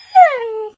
whine.ogg